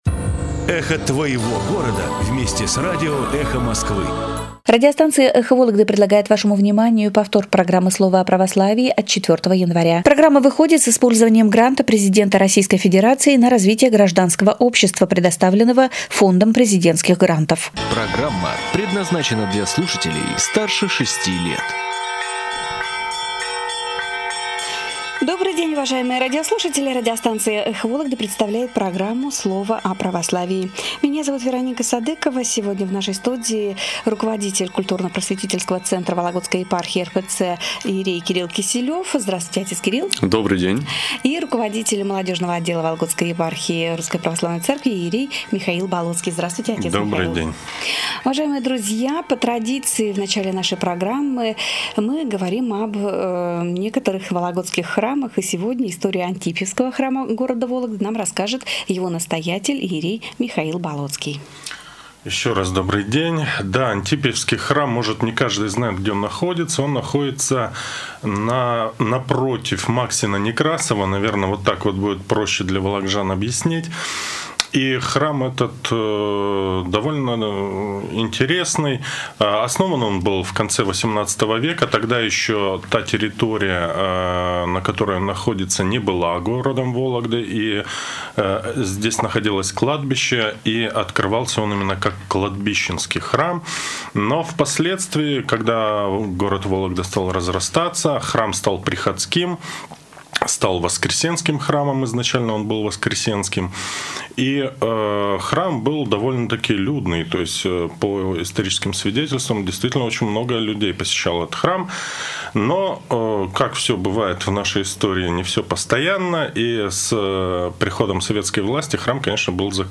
Священники Вологодской епархии выступили в прямом эфире радиостанции "Эхо Вологды"